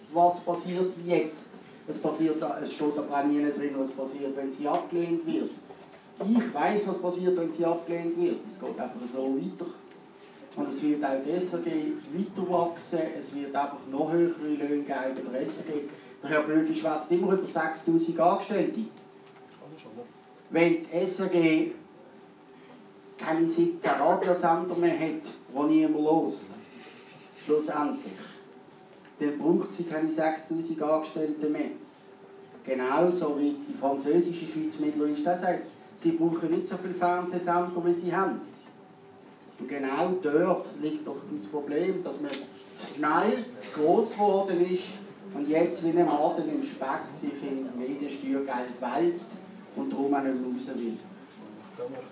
Zwei BDP-Politiker widersprechen einander: